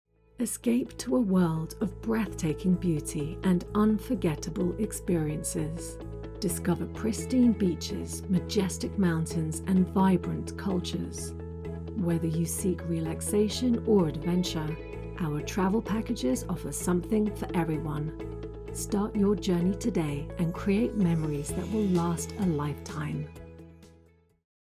Female
Known for a warm, relatable, calm and authoritative tone.
Television Spots
A Voice Demo Recorded For A Television Commercial Audition In The Travel Industry.